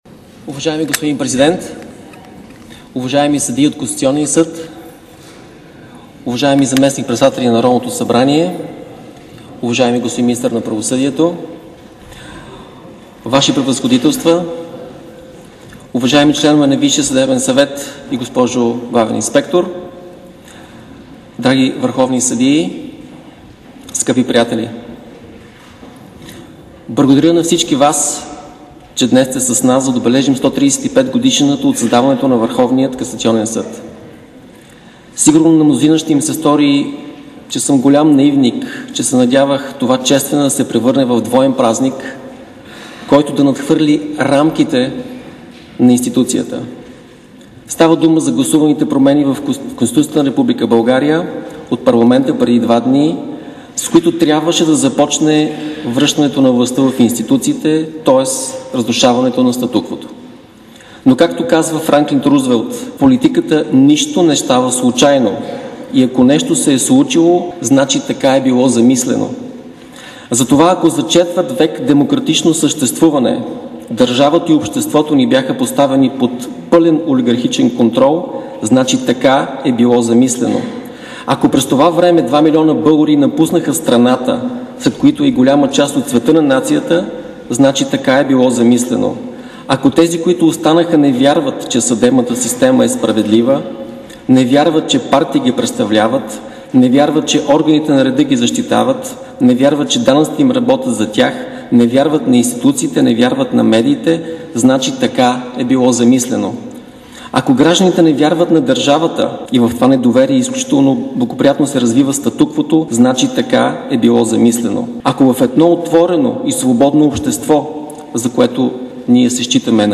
Речта на Лозан Панов по случай 135-годишнината на ВКС
Чуйте цялата реч, която председателят на ВКС Лозан Панов произнесе пред съдиите по случай 135-тата годишнина от създаването на Върховния касационен съд: